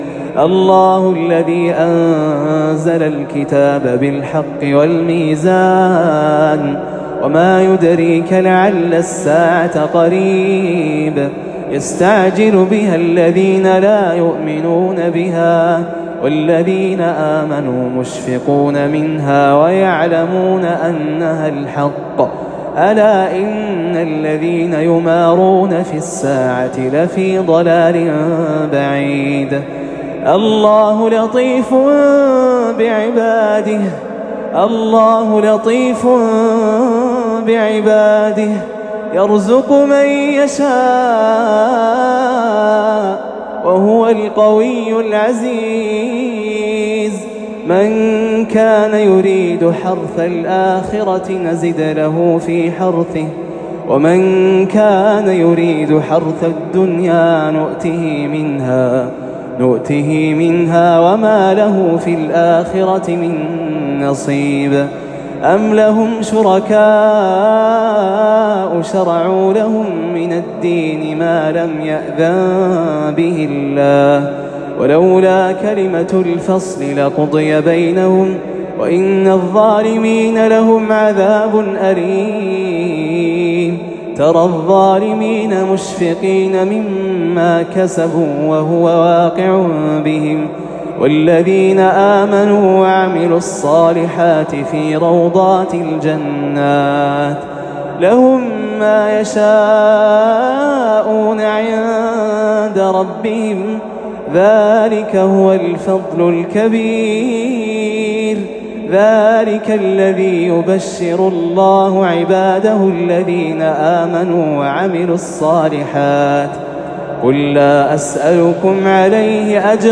تلاوة خاشعة